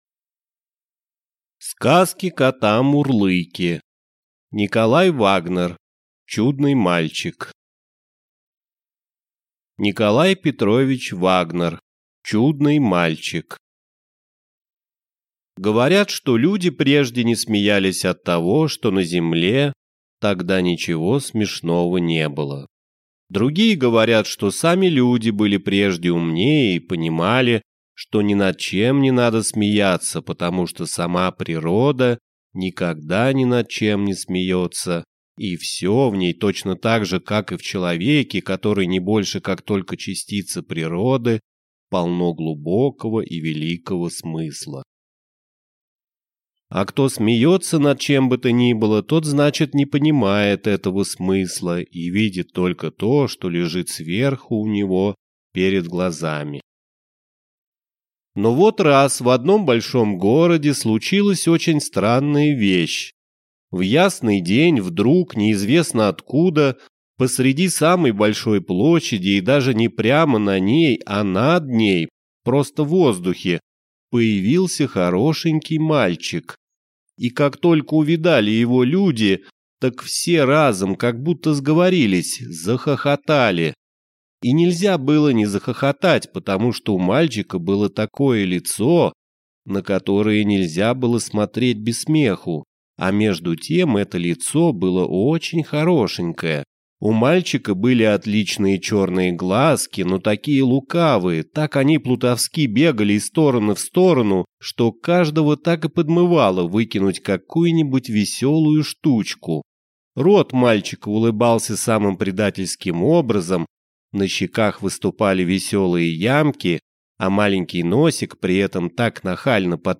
Аудиокнига Чудный мальчик | Библиотека аудиокниг